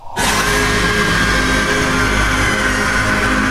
Monster Screamer From Poppy Playtime Chapter 3 Sound Effect Free Download
Monster Screamer From Poppy Playtime Chapter 3